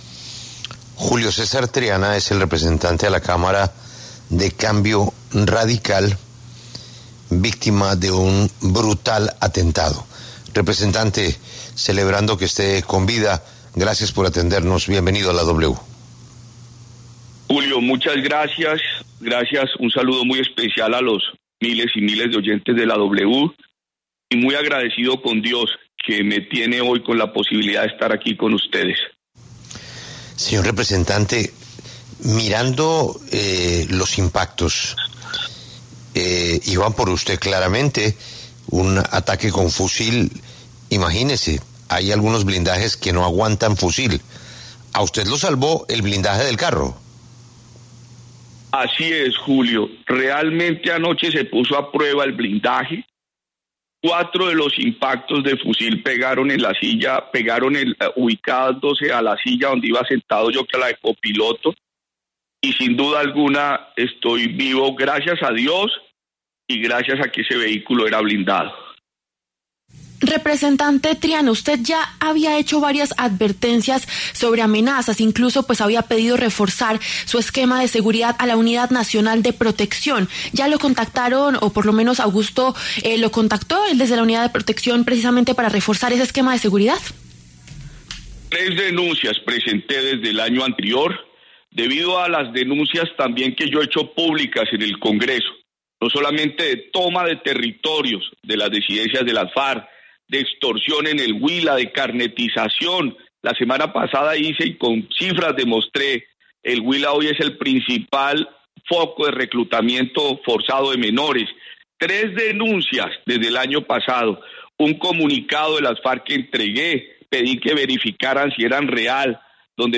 El representante a la Cámara Julio César Triana habló en La W y advirtió que las elecciones del 2026 están en riesgo por cuenta de grupos armados.